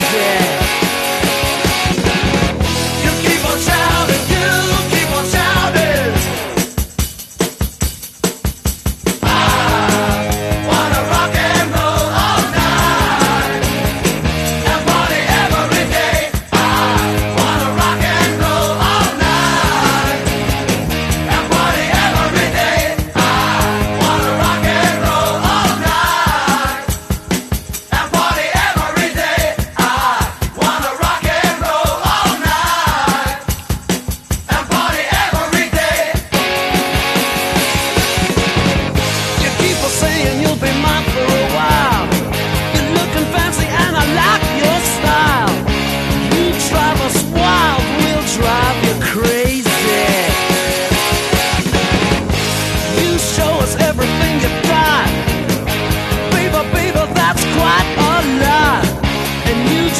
Genre: Glam Rock, Hard Rock